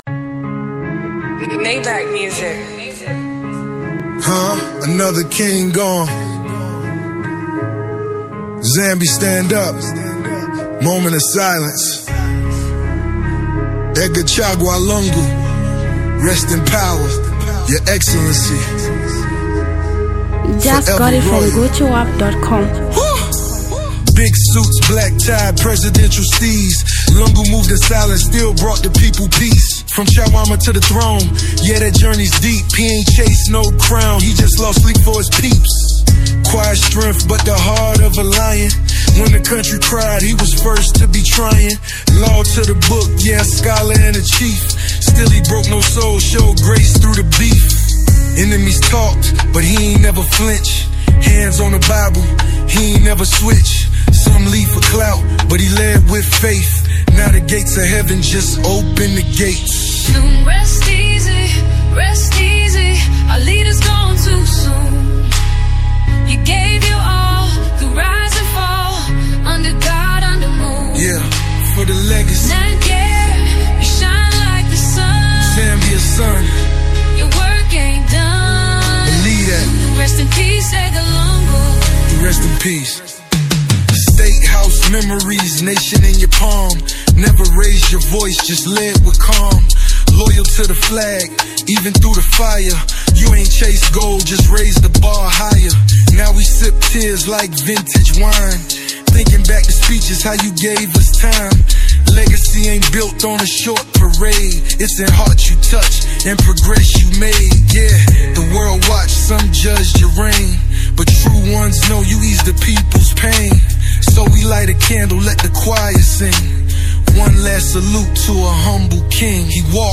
A Powerful Official Tribute Emotional Song
Hip-hop African music